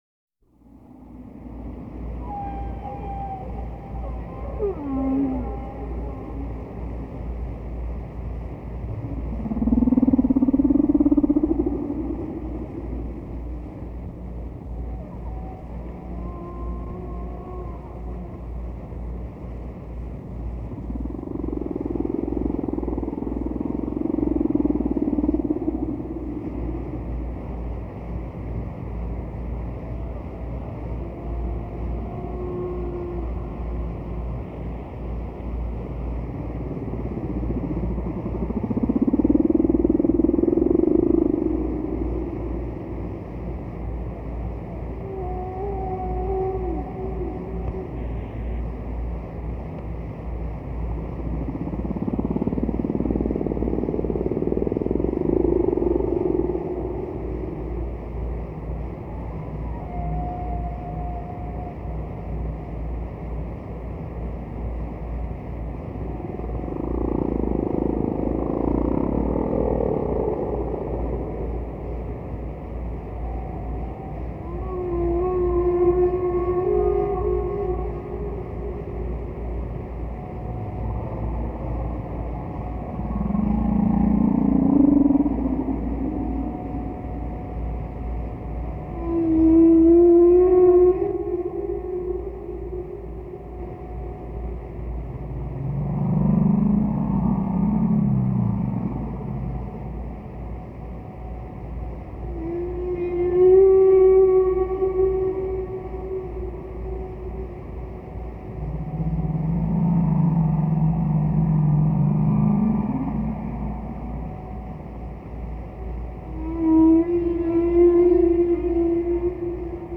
Distant Whale, Songs of the humpback whale3:57
19_distant_whale.mp3